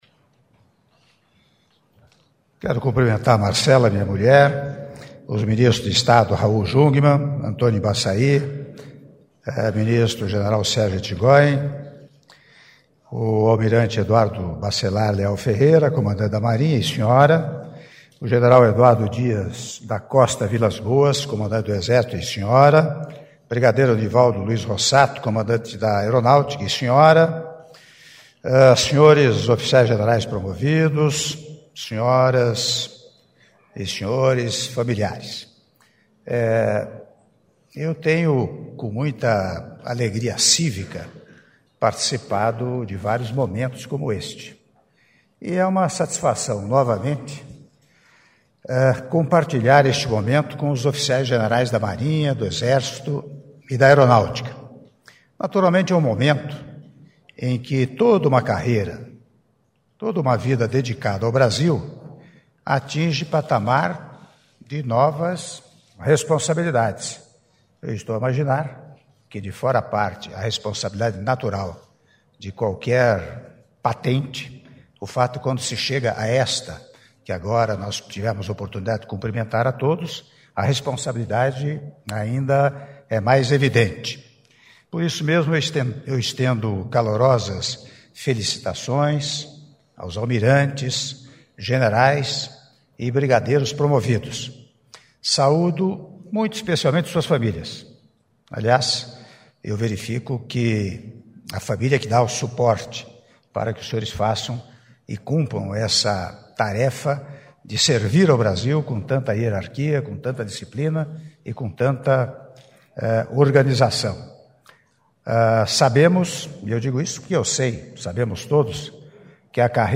Áudio do discurso do Presidente da República, Michel Temer, durante solenidade de apresentação de oficiais-generais promovidos - Brasília/DF (07min16s)